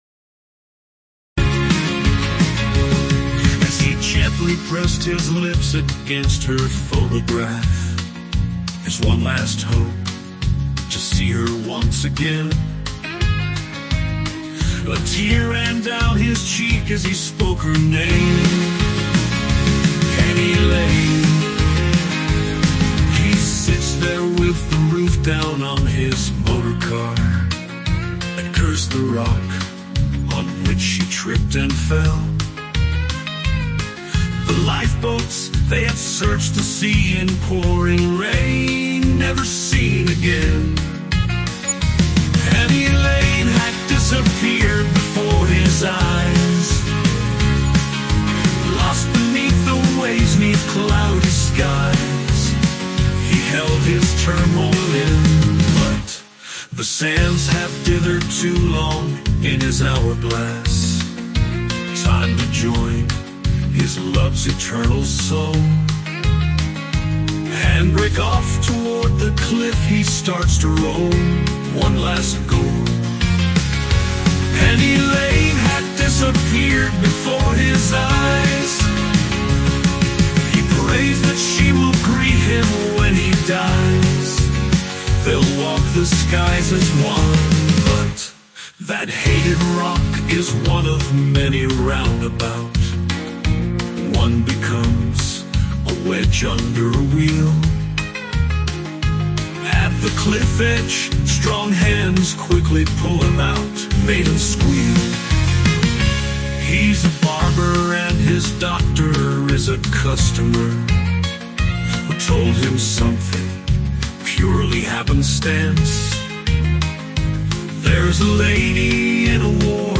Now set to AI generated music.